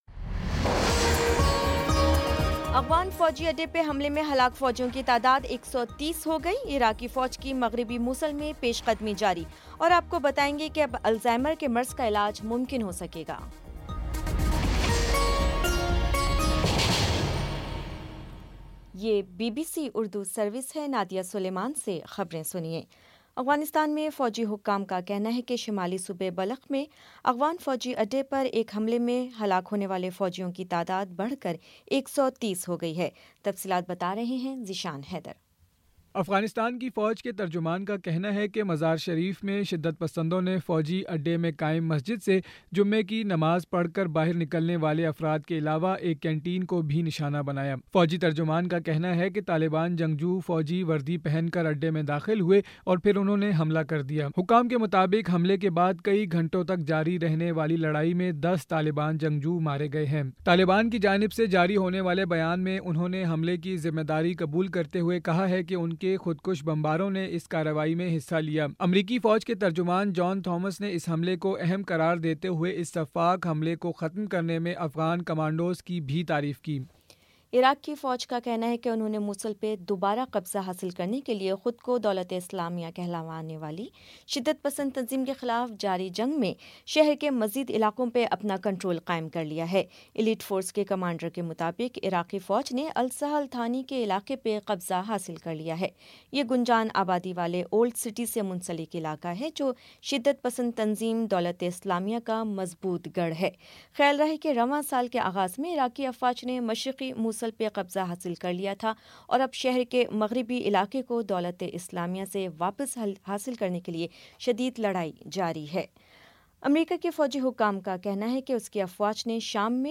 اپریل 22 : شام پانچ بجے کا نیوز بُلیٹن